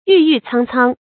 郁郁苍苍 yù yù cāng cāng
郁郁苍苍发音